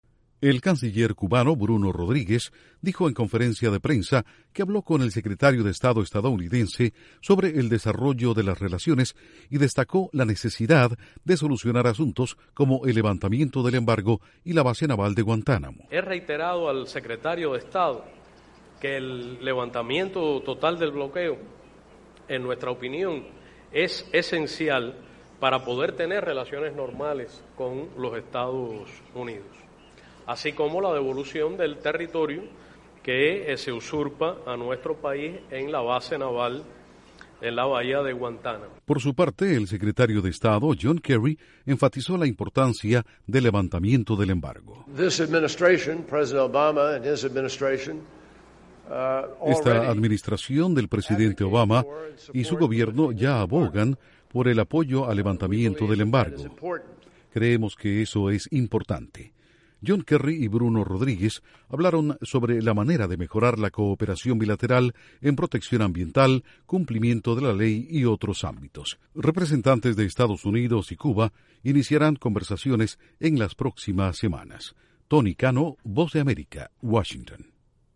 Cuba reitera a Estados Unidos la necesidad de terminar con el embargo económico luego de la reapertura oficial de la embajada estadounidense en La Habana. Informa desde los estudios de la Voz de América en Washington